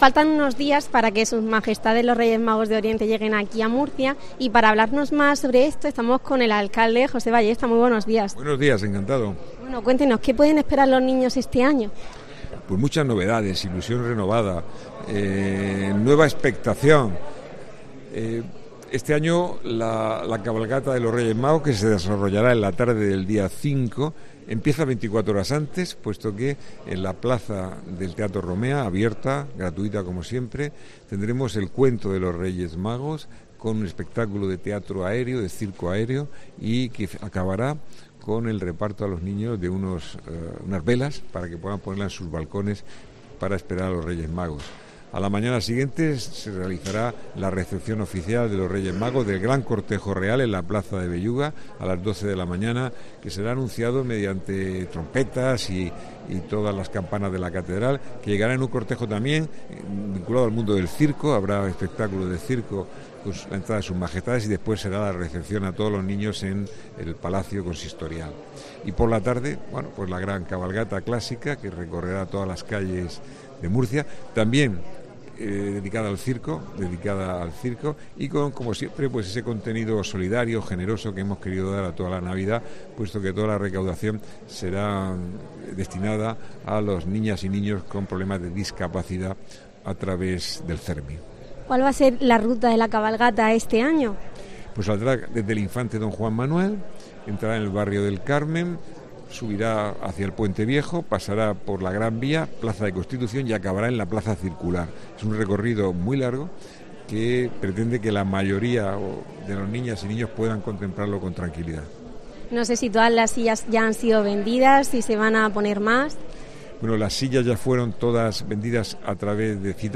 José Ballesta, alcalde de Murcia